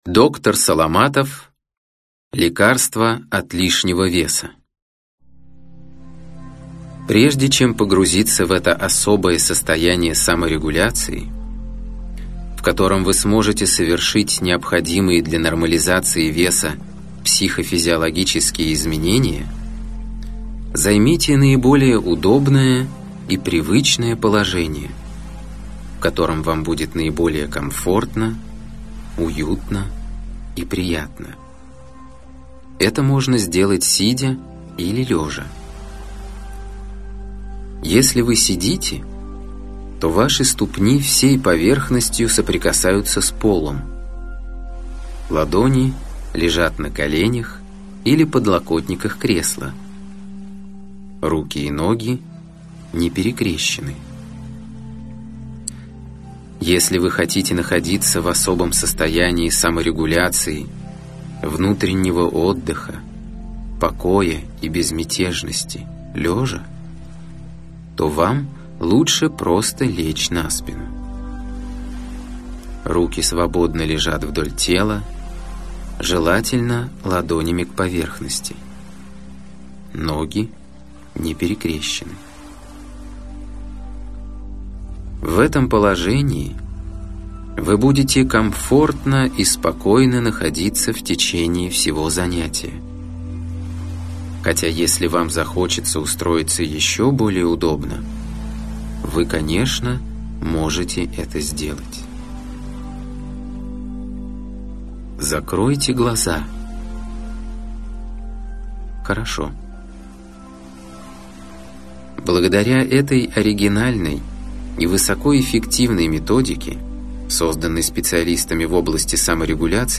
Аудиокнига Лекарство от лишнего веса | Библиотека аудиокниг